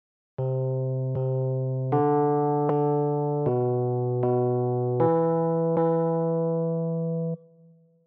6.ルート音のみ